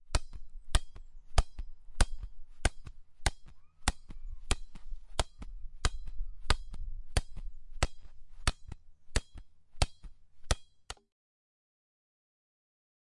花园 " 8.在水泥地面上投掷球
描述：橡胶球，快速，混凝土地板，外面，关闭